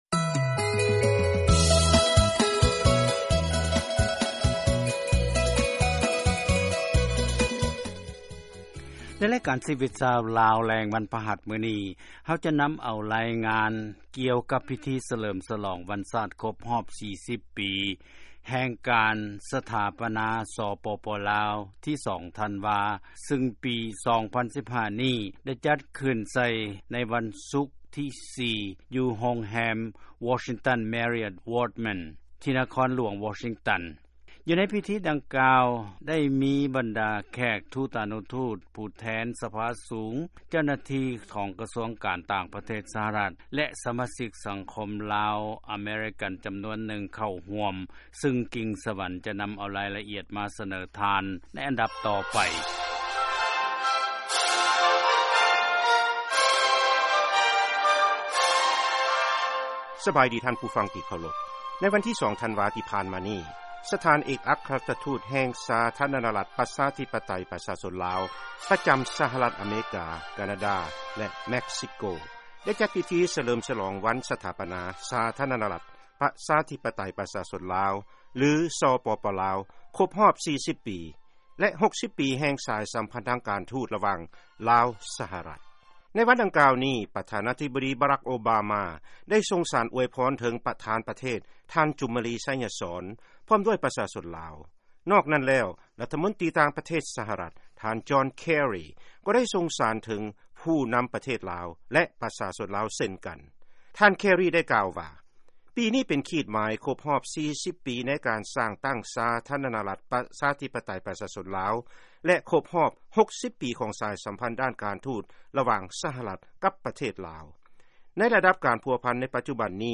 Embed share ເຊິນຟັງ ລາຍງານ ການສະຫລອງວັນຊາດ ສປປ ລາວ ທີ 2 ທັນວາ ທີ ນະຄອນຫລວງ ວໍຊິງຕັນ ດີ.ຊີ by ສຽງອາເມຣິກາ ວີໂອເອລາວ Embed share The code has been copied to your clipboard.